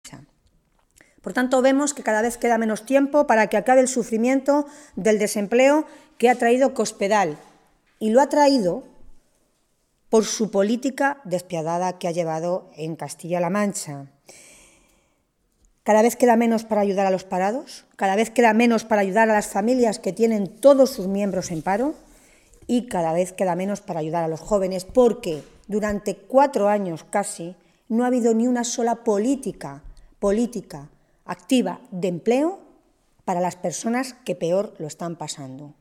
Tolón realizaba estas declaraciones en una rueda de prensa en las que valoró los datos del paro del mes de enero que se han conocido hoy.
Cortes de audio de la rueda de prensa